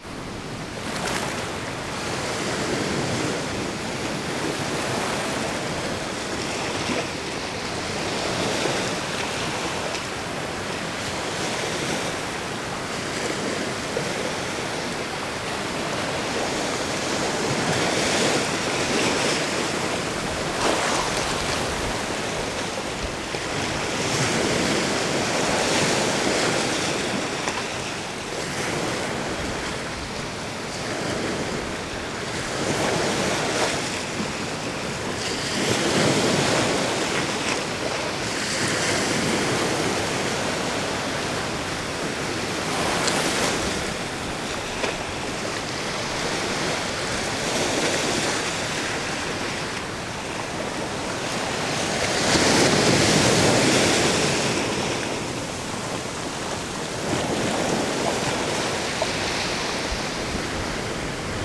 ambientsound_world.wav